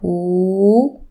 ∨ huu